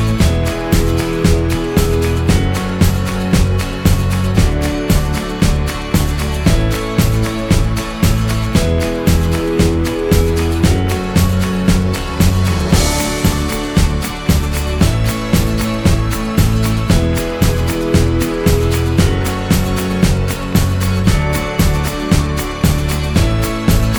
no Backing Vocals Duets 3:44 Buy £1.50